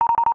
match-confirm.wav